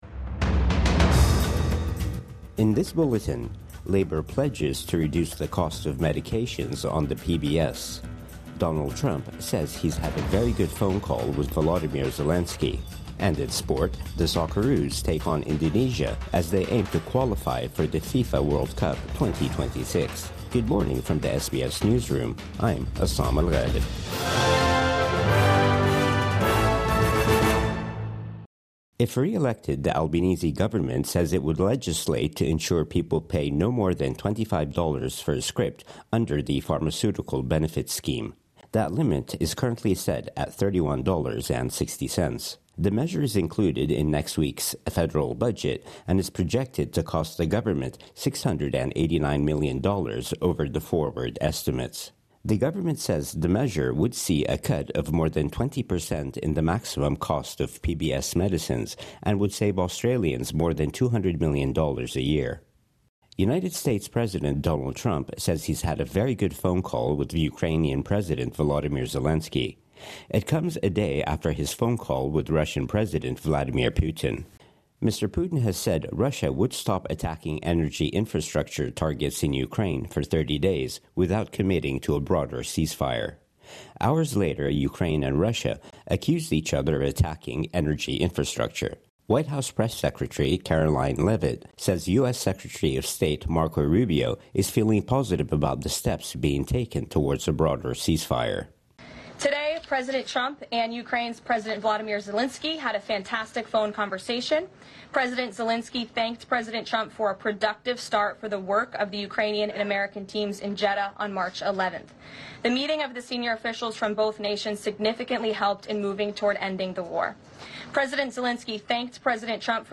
Labor pledges to lower cap on PBS medicines | Morning News Bulletin 20 March 2025